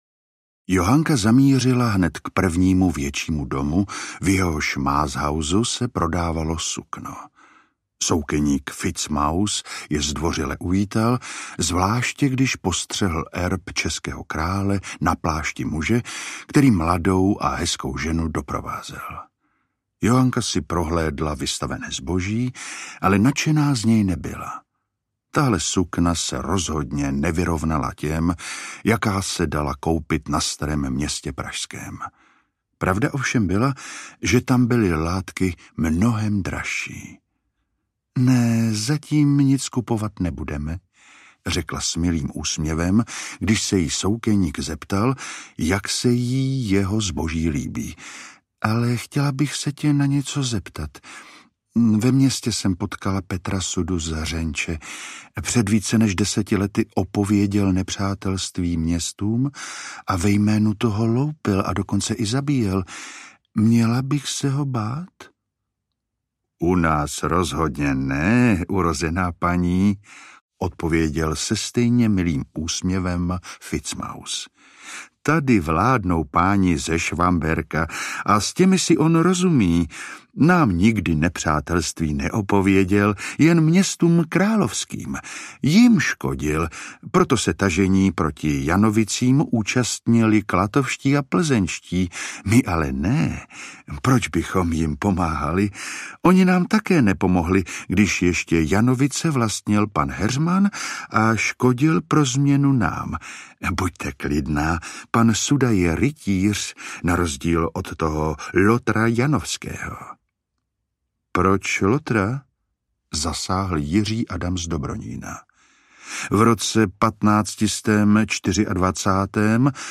Audiobook
Read: Martin Zahálka